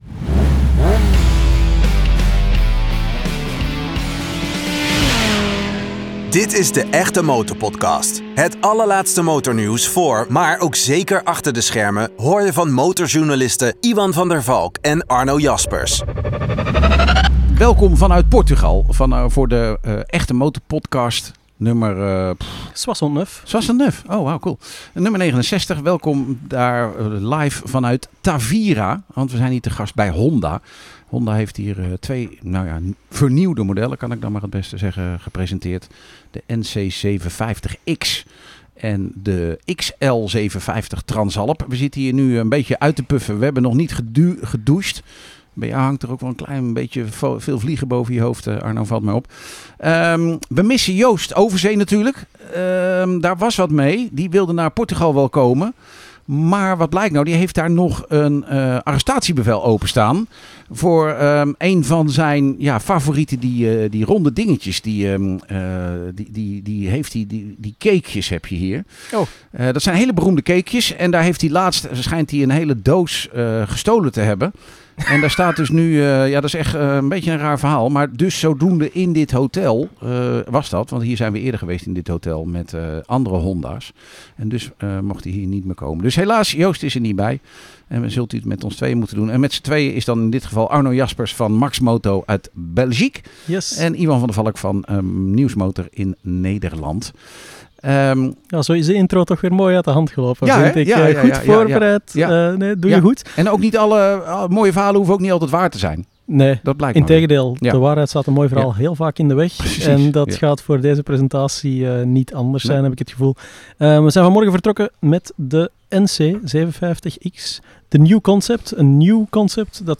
De MotorPodcast gaat over motorrijden, motornieuws, motorsport en (vooral) de achtergronden uit de motorwereld. Vaste deelnemers zijn ervaren motorjournalisten